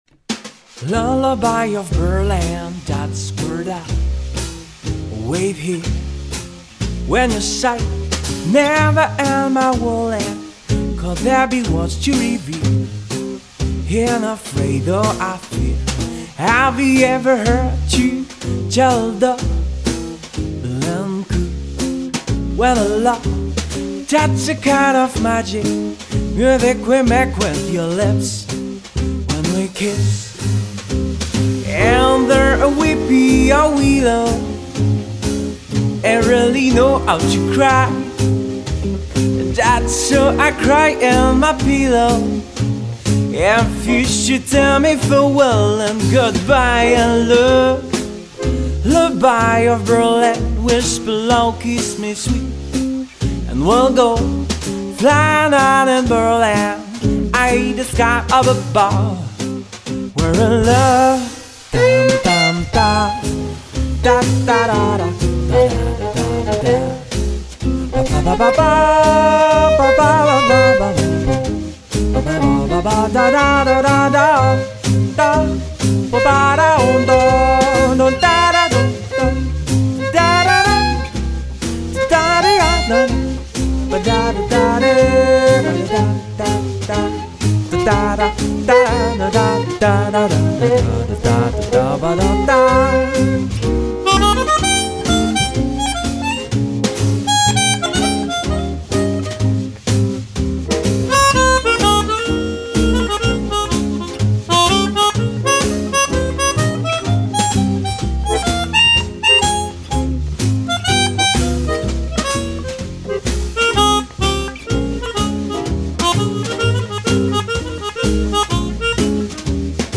violon
accordéon, accordina, chant
guitare
contrebasse
batterie